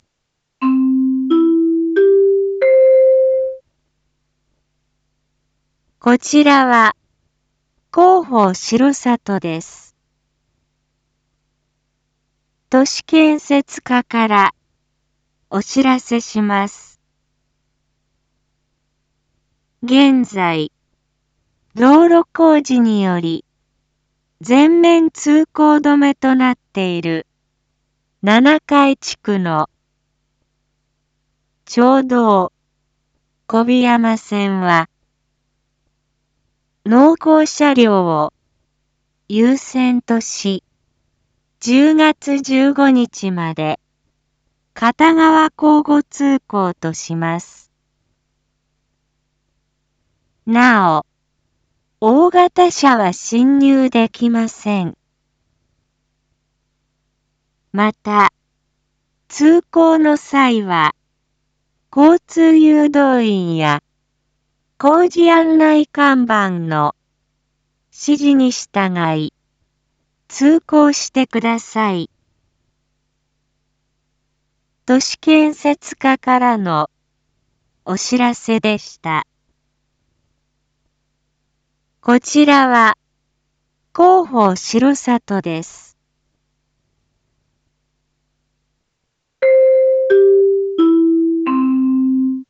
Back Home 一般放送情報 音声放送 再生 一般放送情報 登録日時：2023-10-13 19:01:34 タイトル：町道４号線の片側車線一時開放について インフォメーション：こちらは、広報しろさとです。